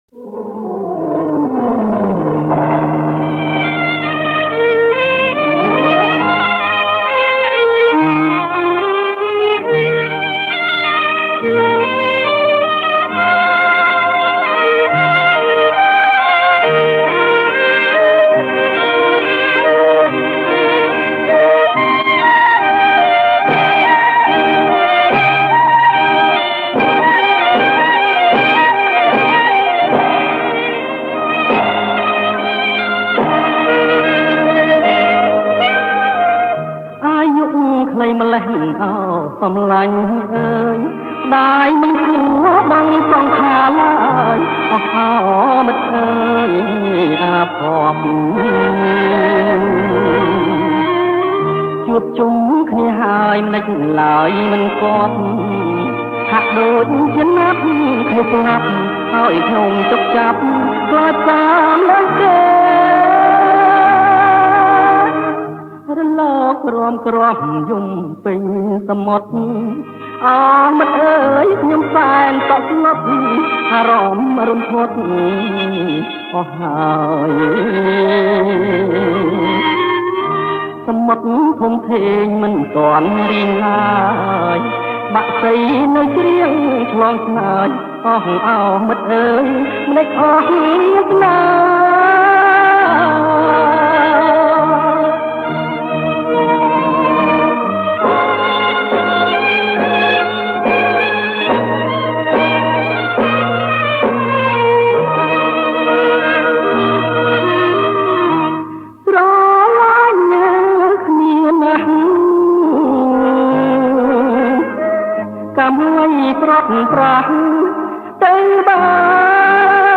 • ប្រគំជាចង្វាក់ Slow
ប្រគំជាចង្វាក់ Slow